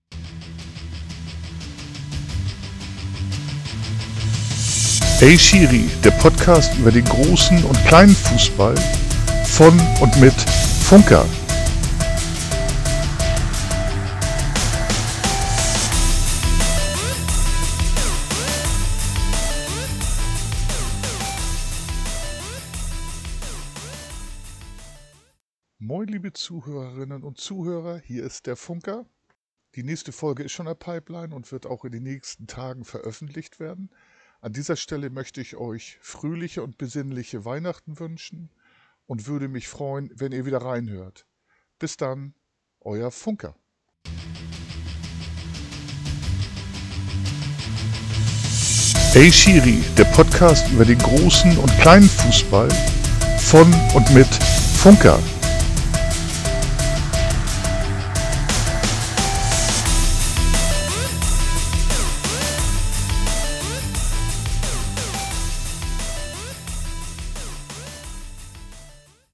Intormusik